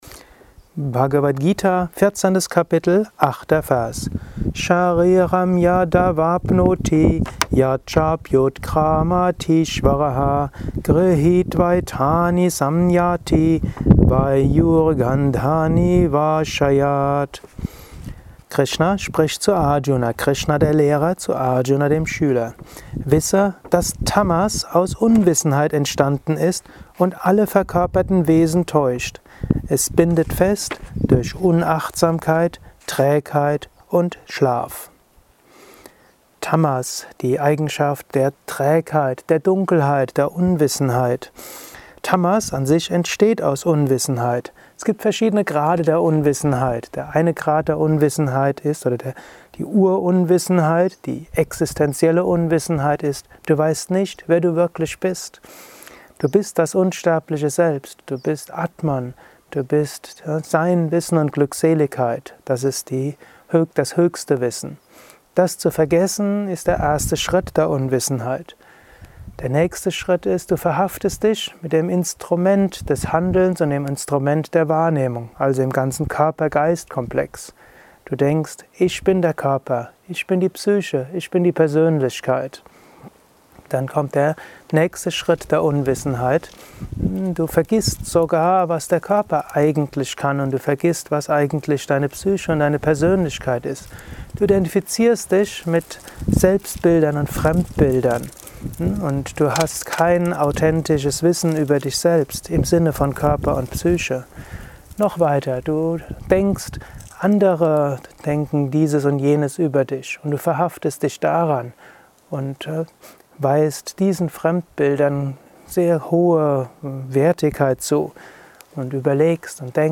Kurzvorträge
Aufnahme speziell für diesen Podcast.